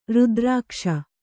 ♪ rudrākṣa